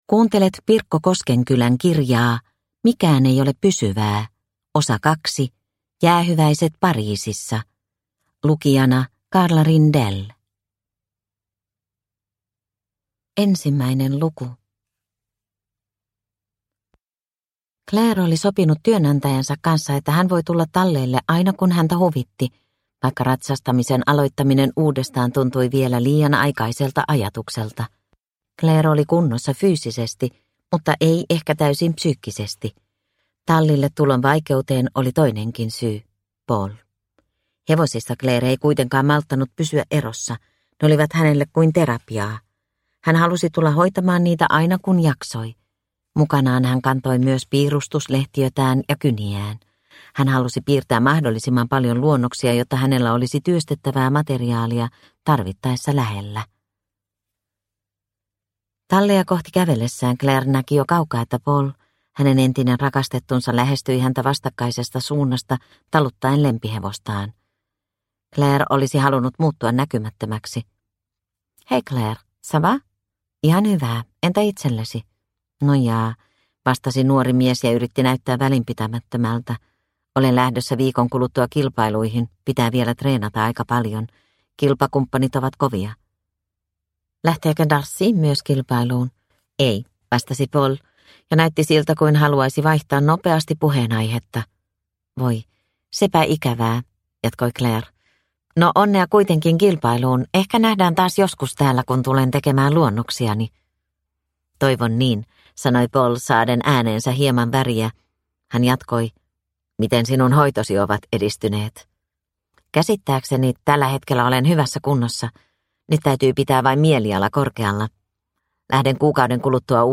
Mikään ei ole pysyvää - Osa 2 – Ljudbok – Laddas ner
Uppläsare: